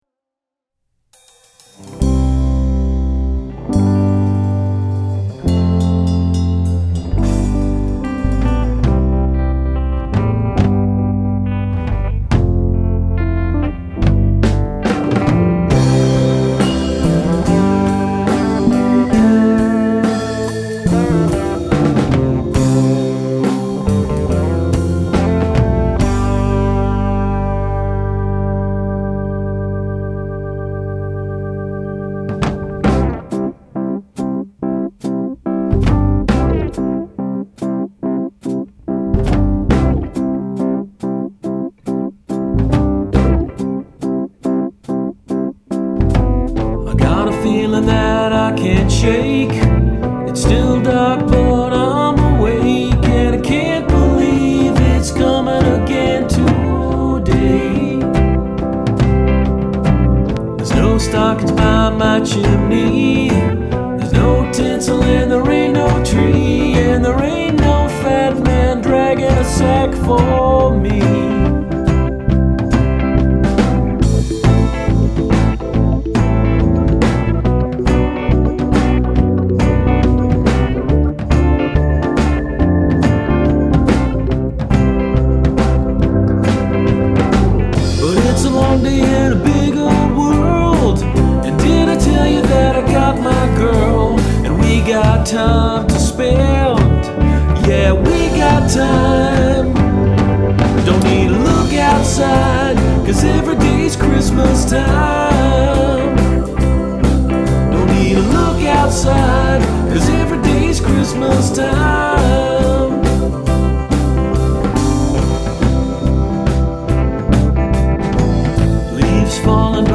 Well, here they are in all their ancient, weirdly mixed and overstuffed glory.